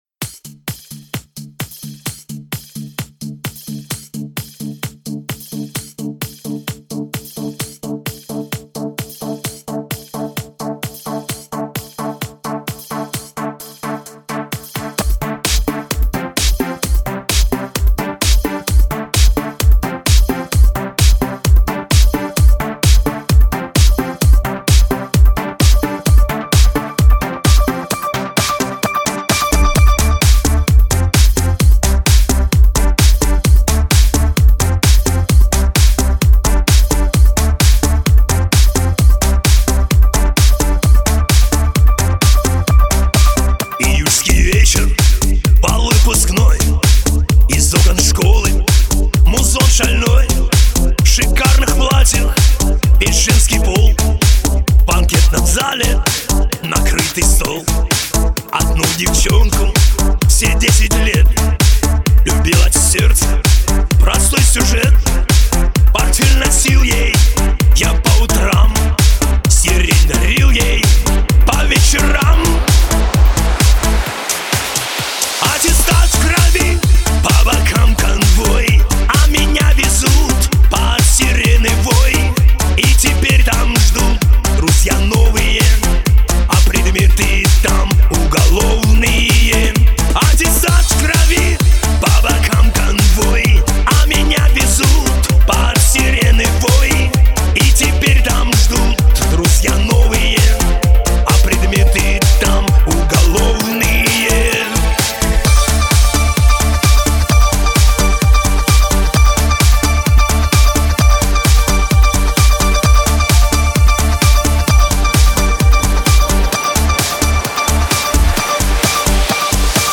На мобилку » Mp3 » Шансон